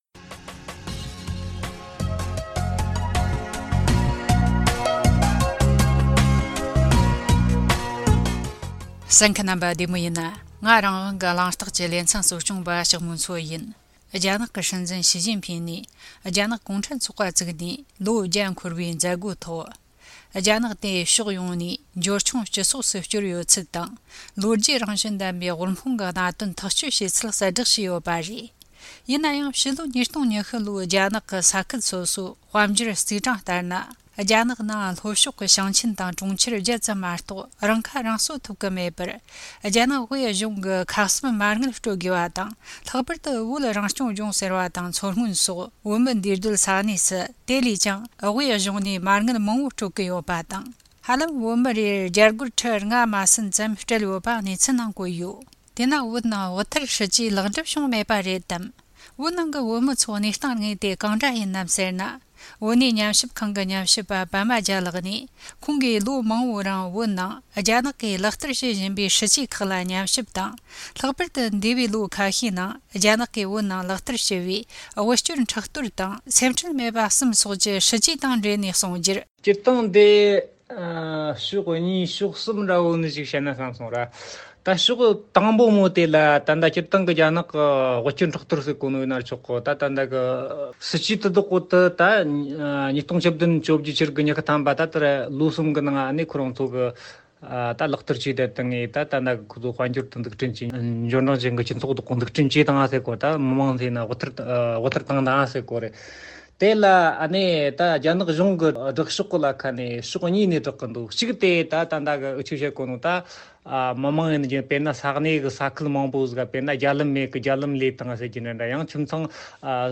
འབྲེལ་ཡོད་ཉམས་ཞིབ་པ་མཉམ་དུ་གླེང་མོལ་བྱས་བར་གསན་རོགས་གནོངས།